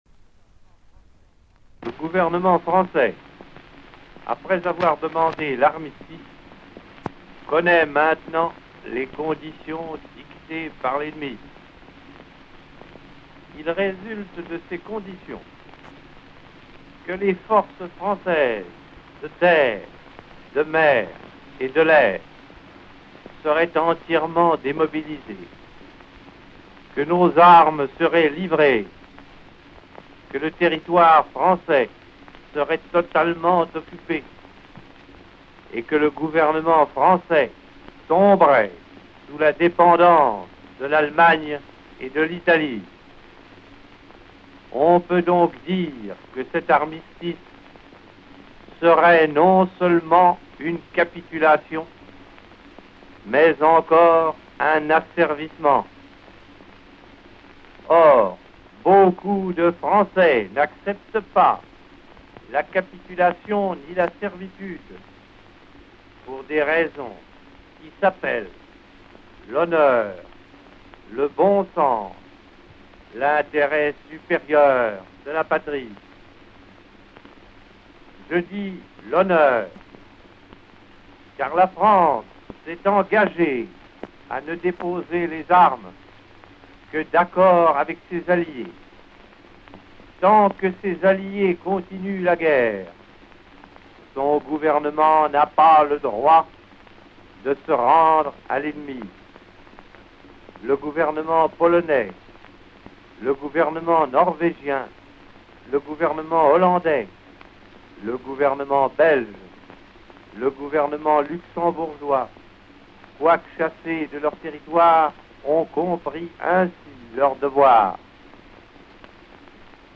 18 juin 1940 : De Gaulle appelle de Londres les français à l'y rejoindre pour continuer le combat.